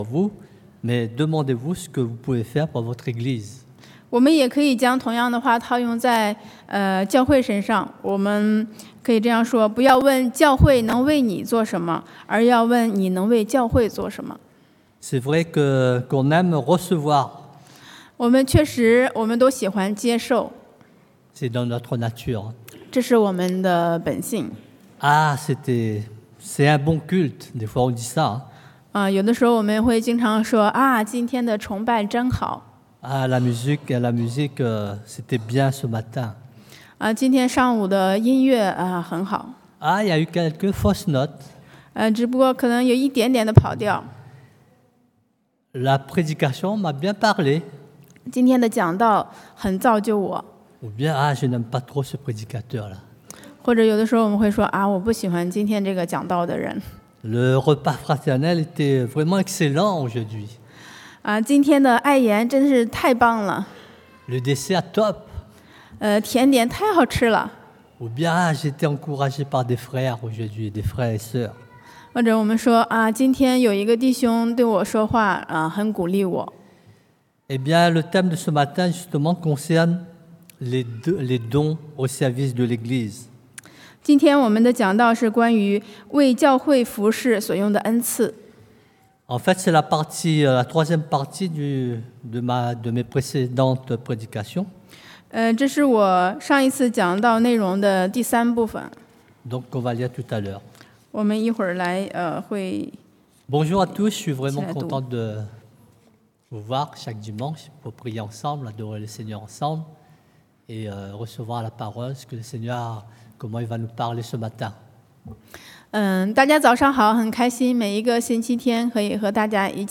Passage: Romains 罗马书 12 :1-8 Type De Service: Predication du dimanche